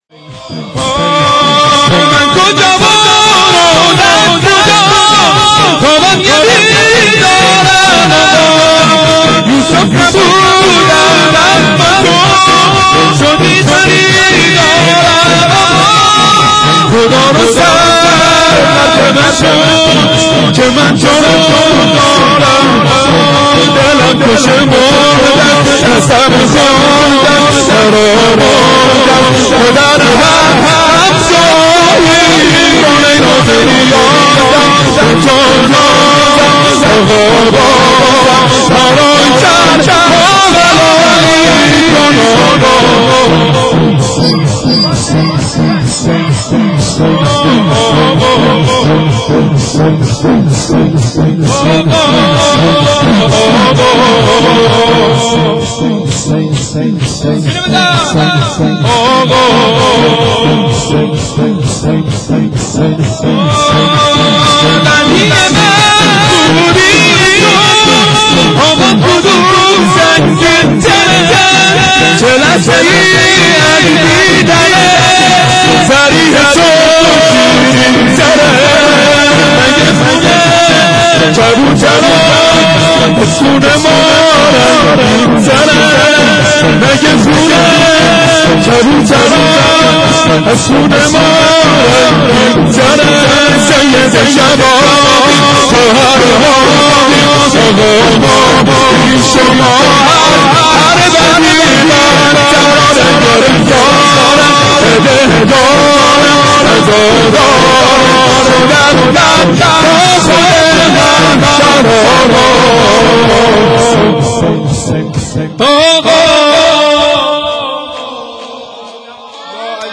شور
شب 21 رمضان 92